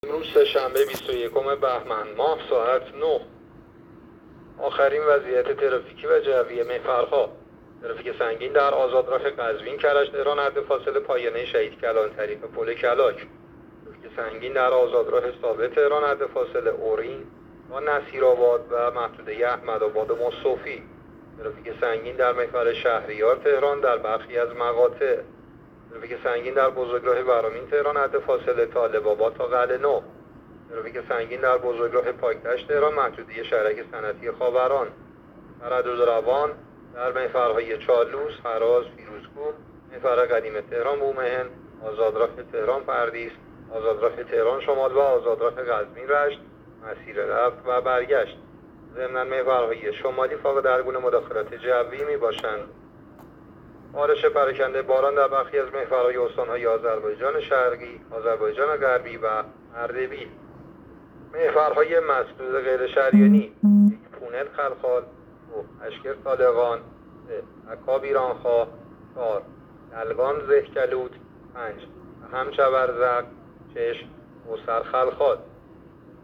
گزارش رادیو اینترنتی از آخرین وضعیت ترافیکی جاده‌ها ساعت ۹ بیست و یکم بهمن؛